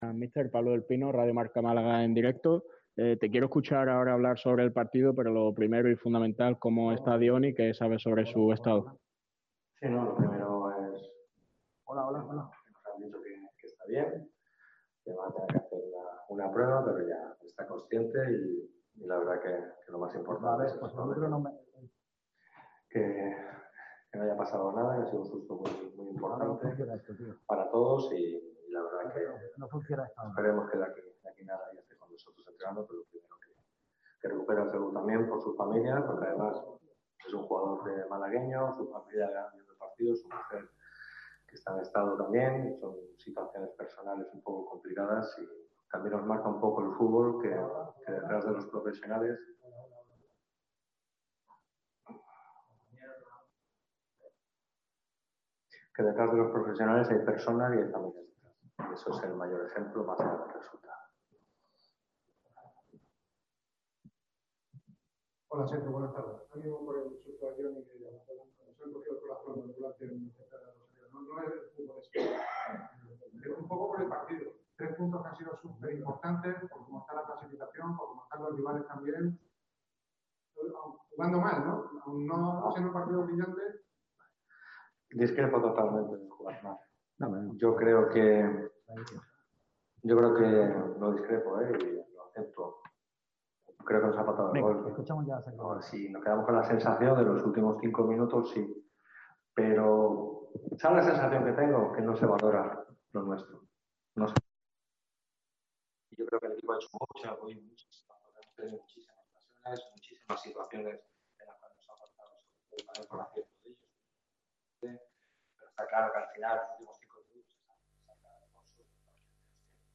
Sergio Pellicer compareció en rueda de prensa tras la victoria ante el CF Intercity (1-0) que coloca al Málaga CF a cinco puntos de la cabeza. El de Nules se mostró satisfecho con el juego del equipo y considera que entramos en la zona en la que «se cuecen las habas» en las últimas 10 jornadas.